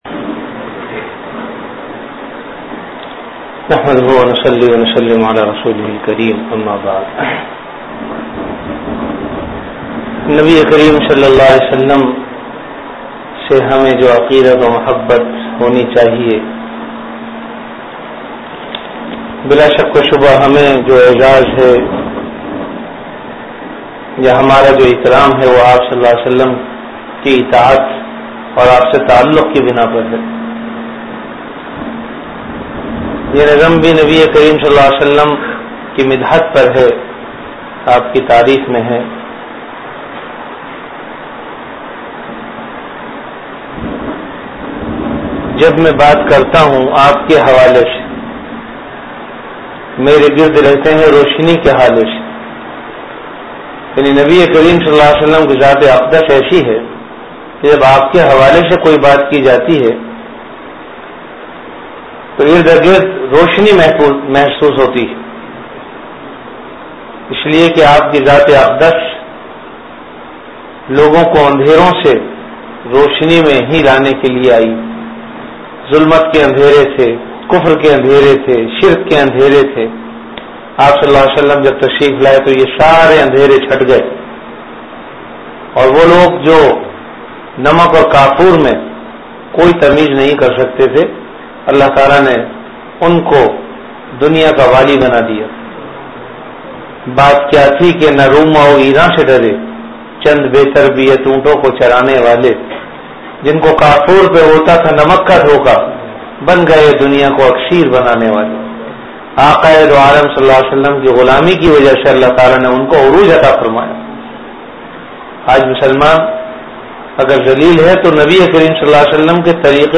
An Islamic audio bayan
Delivered at Home.